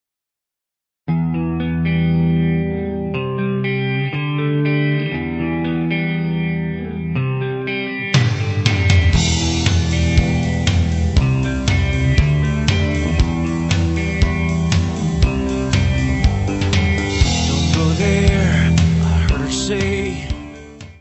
: stereo; 12 cm
Área:  Pop / Rock